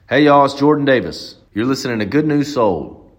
LINER Jordan Davis (Good News Sold) 2